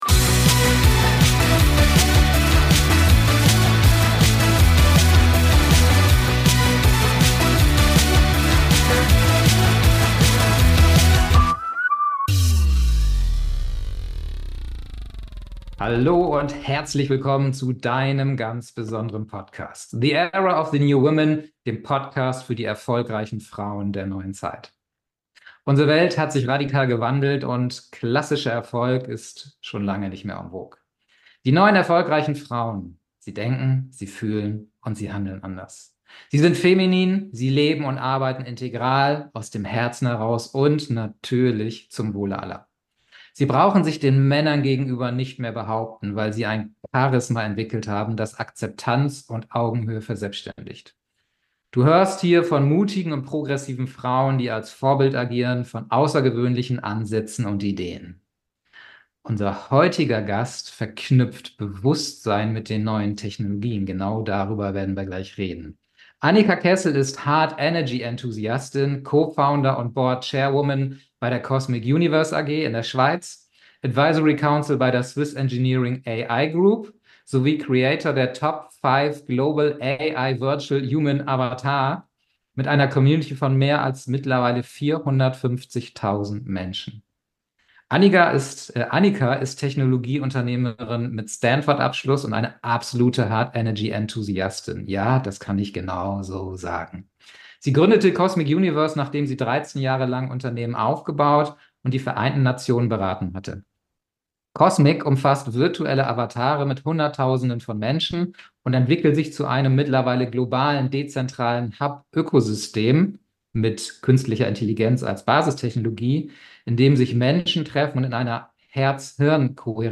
#013 Brückenbauerin zwischen Corporate World und New Technology. Interview